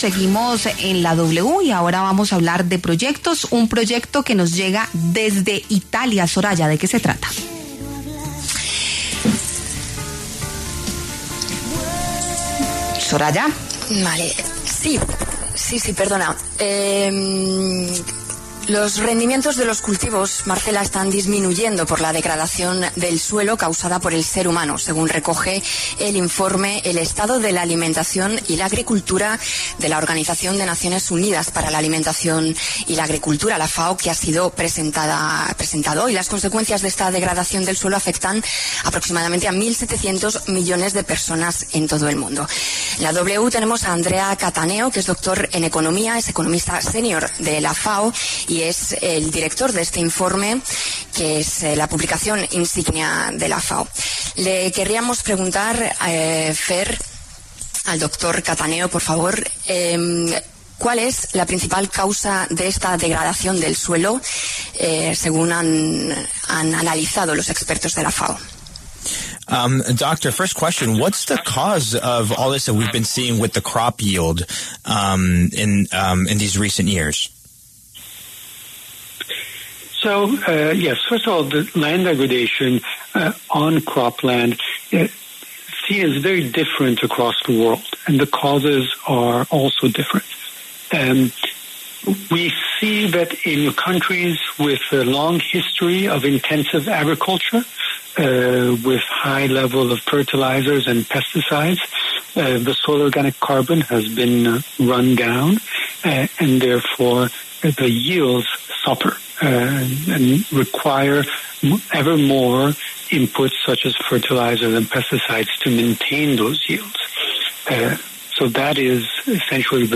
dialogó con La W sobre la disminución en el rendimiento de los cultivos por la degradación de los suelos en diferentes lugares del planeta.